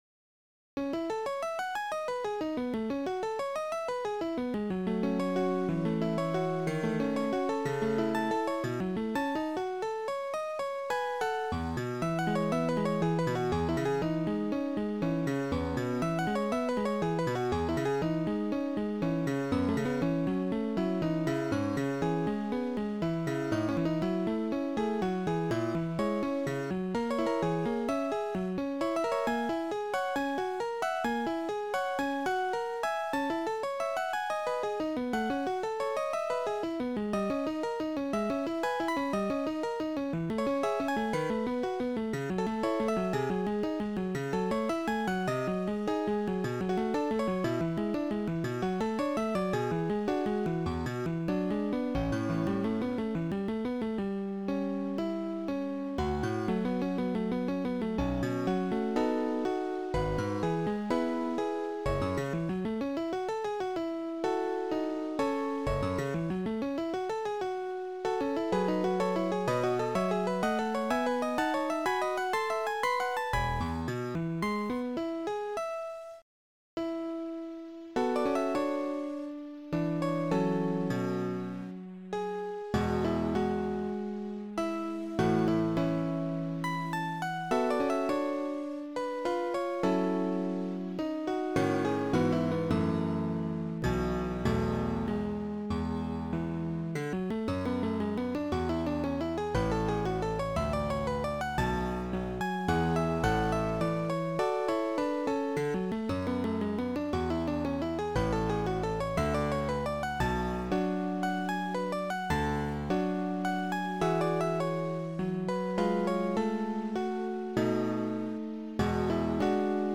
got tempo map now
great tempi.